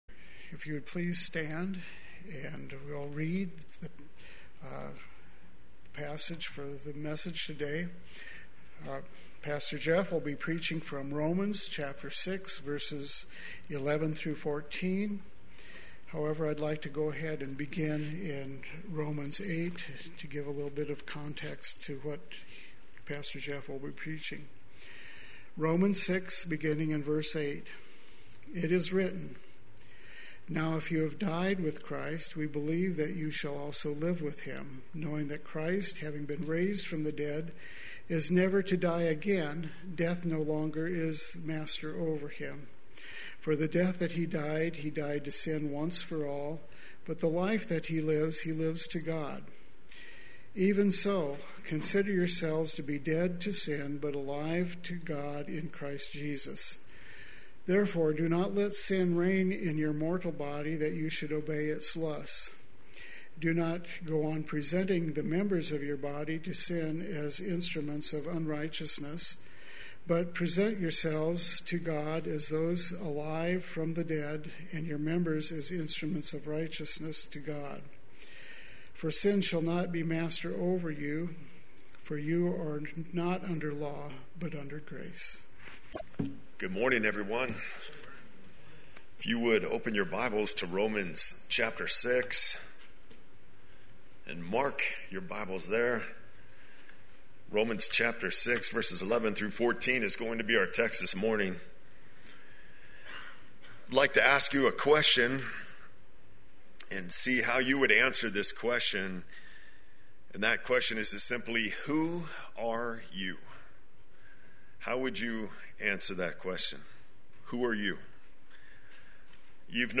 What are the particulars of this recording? Living Your True Image Sunday Worship